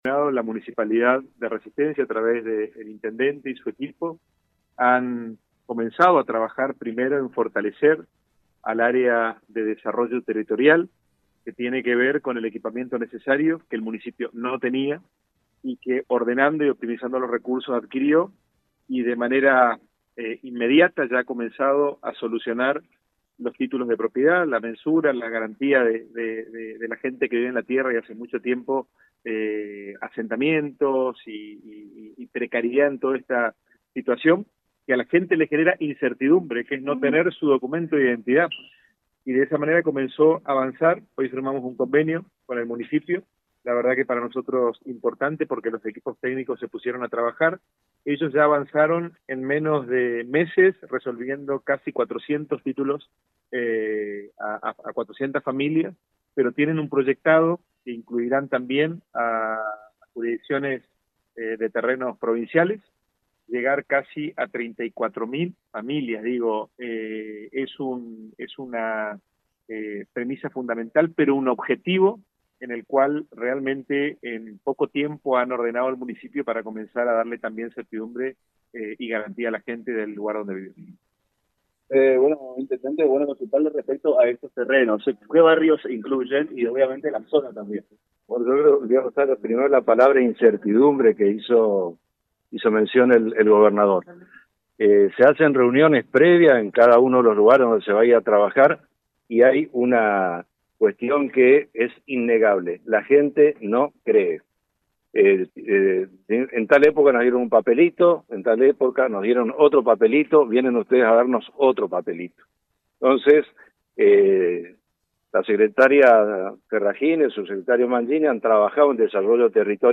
En un acto realizado en el Salón de Acuerdos de Casa de Gobierno, el gobernador Leandro Zdero y el intendente de Resistencia, Roy Nikisch, firmaron un convenio fundamental para avanzar en la regularización dominial de tierras ocupadas por miles de familias en la capital chaqueña.